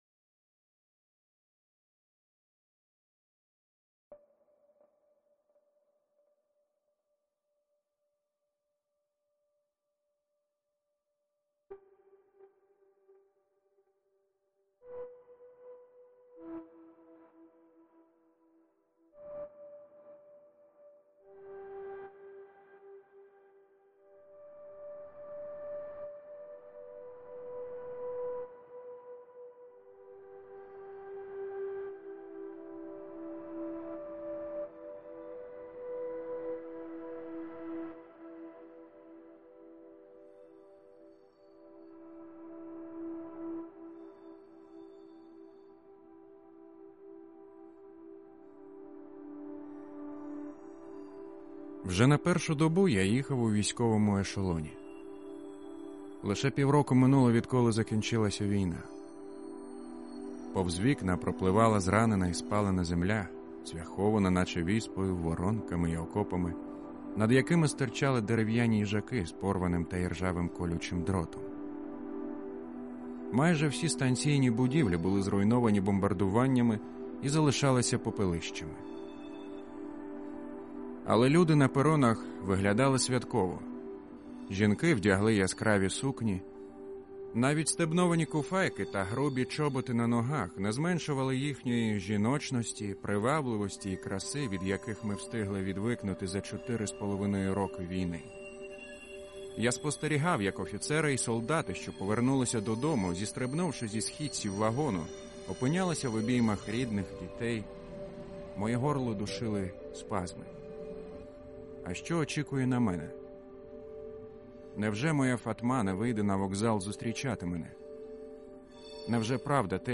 «Я ‒ ваш цар і бог» ‒ аудіокнига за мотивами повісті класика кримськотатарської літератури Шаміля Алядіна